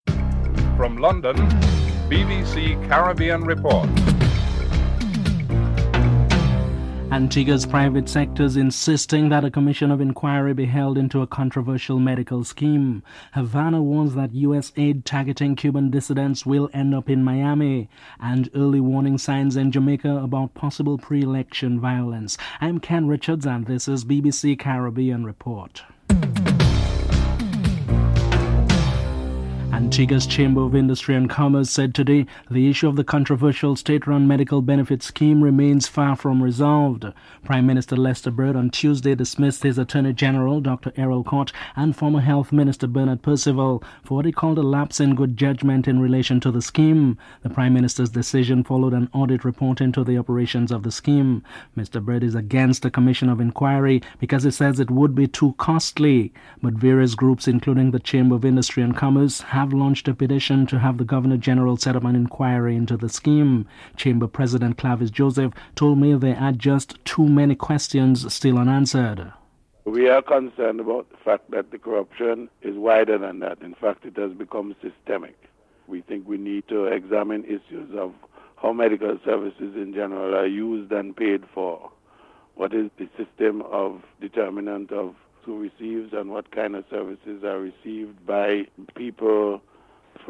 The British Broadcasting Corporation
1. Headlines (00:00-00:29)